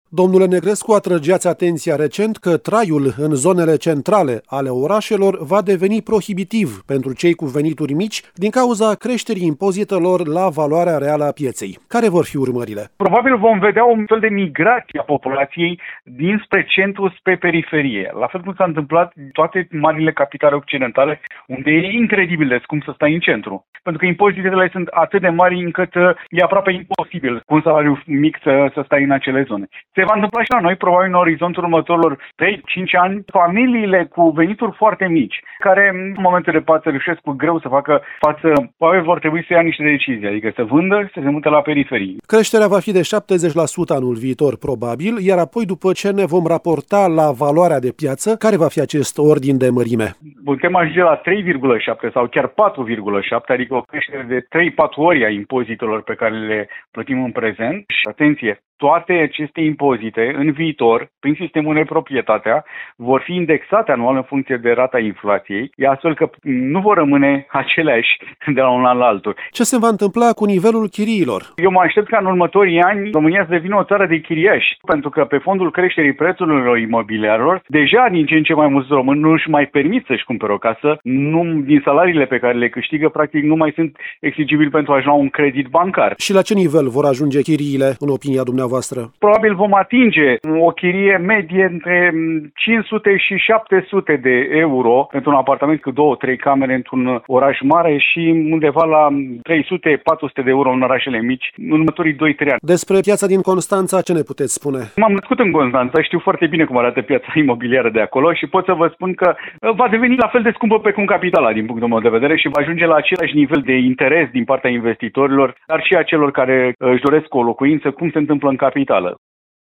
Mai mult, chiriile se vor majora semnificativ, întrucât cererea va exploda, din cauza faptului că foarte puțini români vor mai putea să obțină credite pentru achiziționarea unei locuințe. Avem și un pronostic legat de Constanța. Îl aflăm din interviul următor